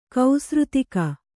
♪ kausřtika